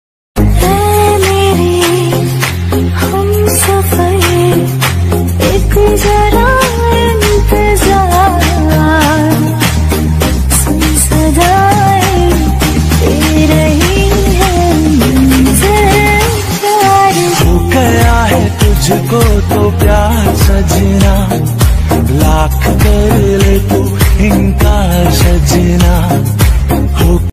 Romantic Ringtone